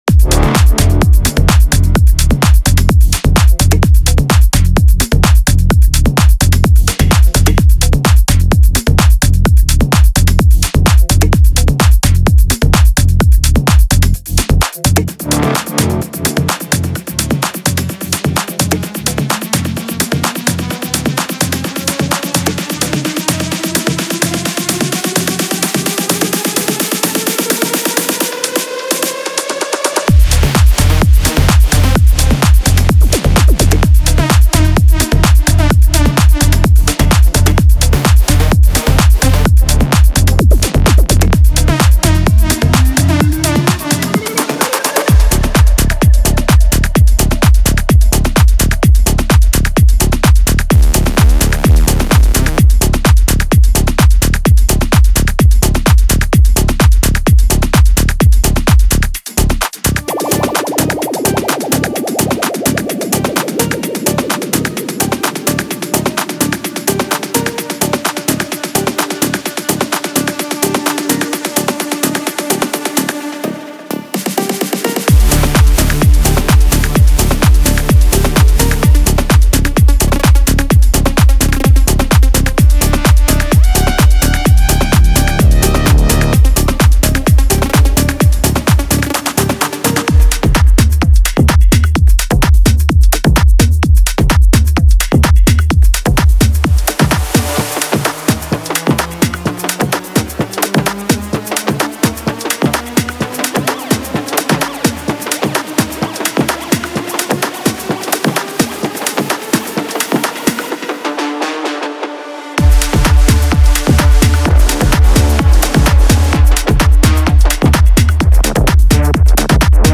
Genre:Tech House
デモサウンドはコチラ↓